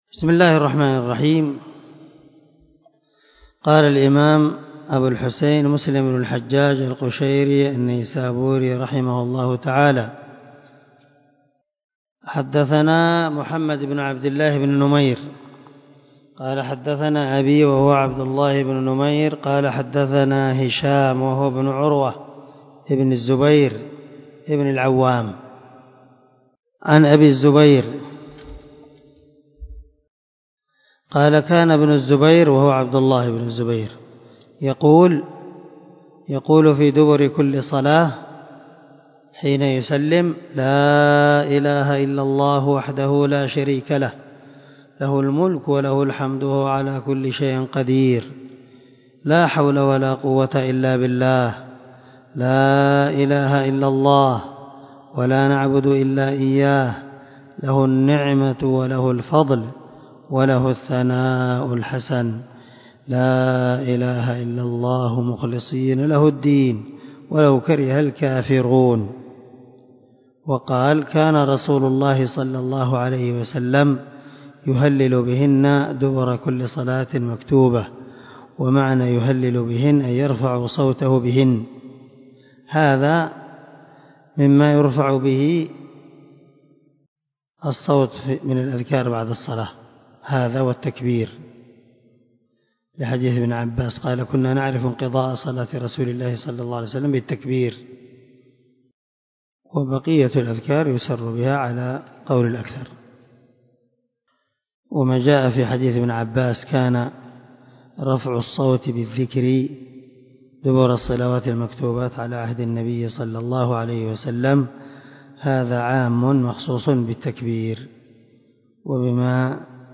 376الدرس 48 من شرح كتاب المساجد ومواضع الصلاة حديث رقم ( 594 ) من صحيح مسلم
دار الحديث- المَحاوِلة- الصبي